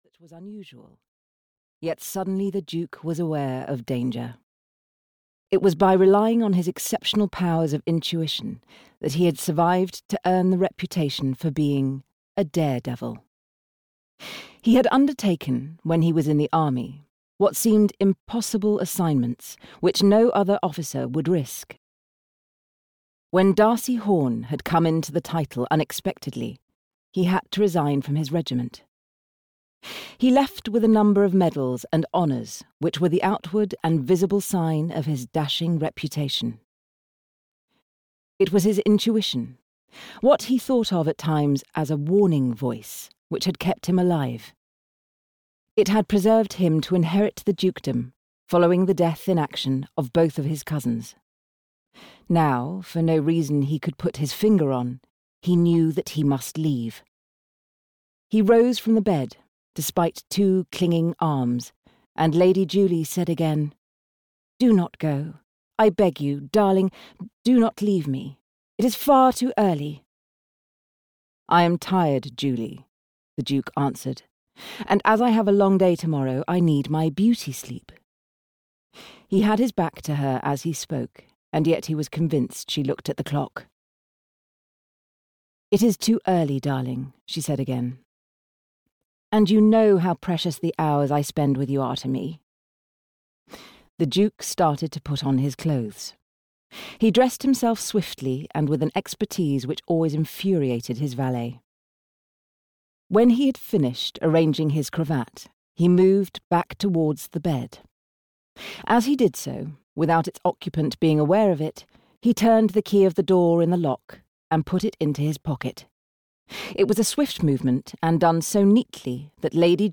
Audio knihaThe Daredevil Duke (EN)
Ukázka z knihy
• InterpretIngrid Oliver